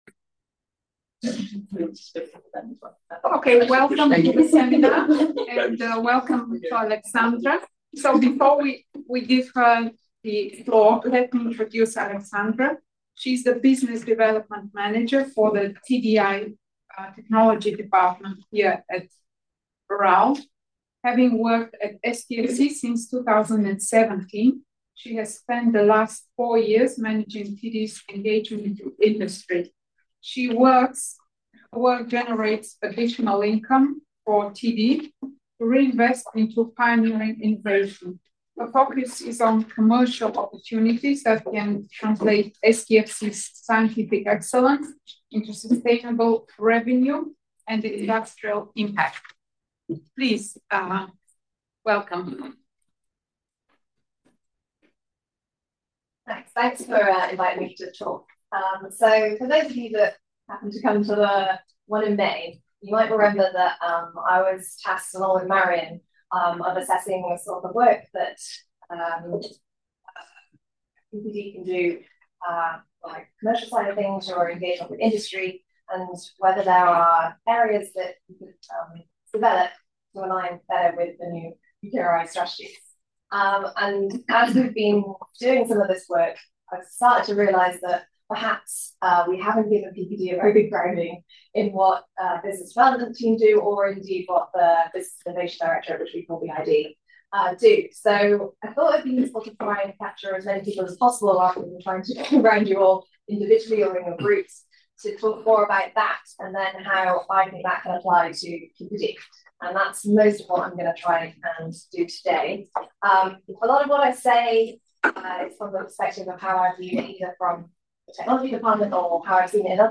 This seminar will consider how PPD can use knowledge generated from the science programme to work on industry-led projects and diversify income streams into the department.